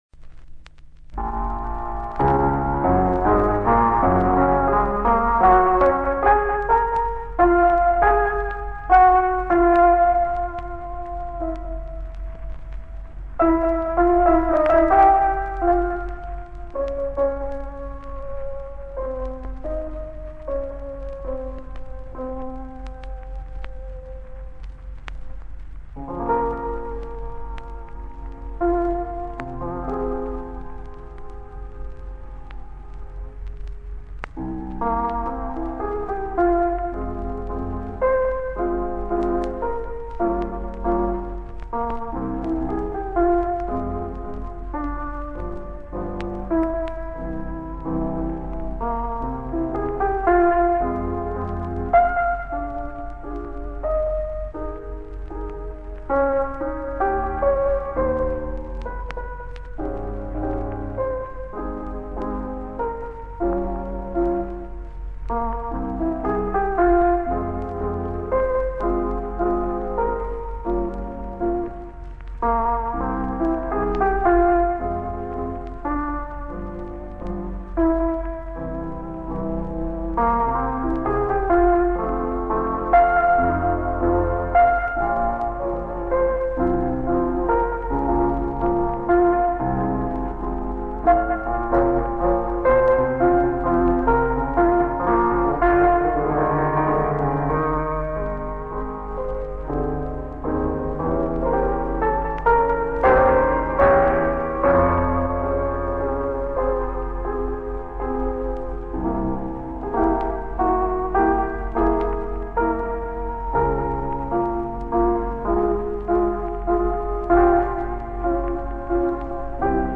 Piano
: 33 1/3 rpm, mono.
Música para piano